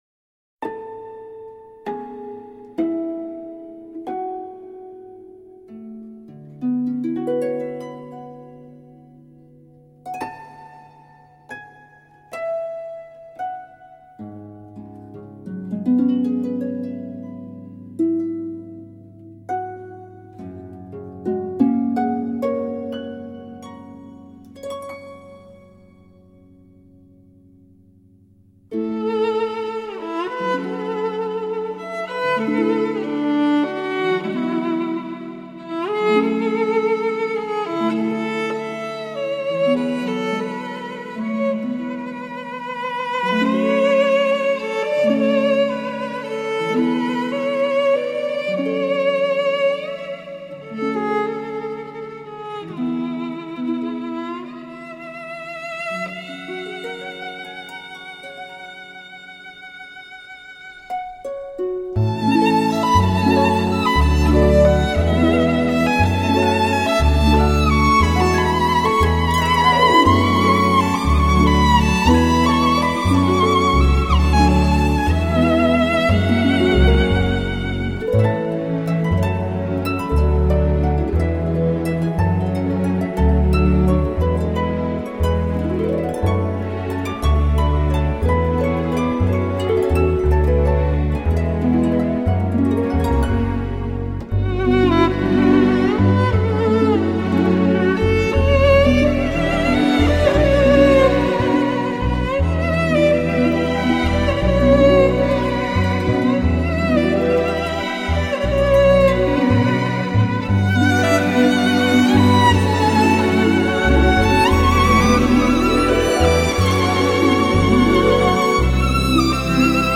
温柔之中蕴含深刻情感